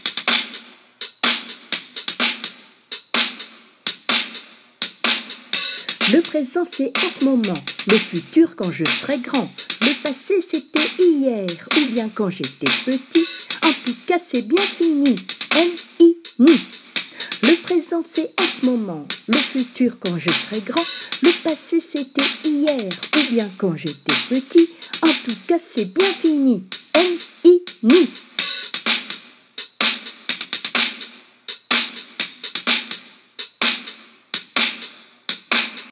Je réalise que je n’avais jamais mis en ligne cette « comptine rythmée »(*) que je faisais avec mes CE1-CE2 [2009] pour leur faire mémoriser les notions de passé, de présent et de futur.
Rap-du-passe-present-futur.m4a